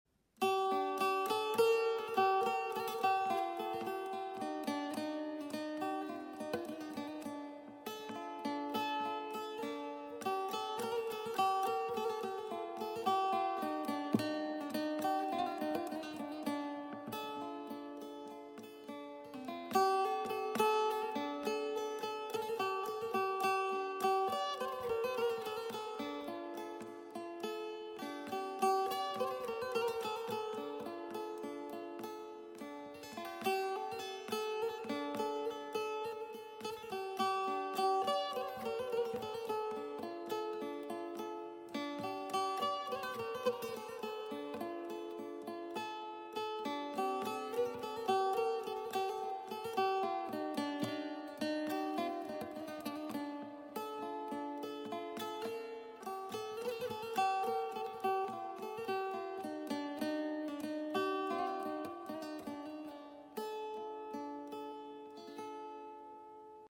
SEAT LEON 1 2TSI EXHAUST Sound Effects Free Download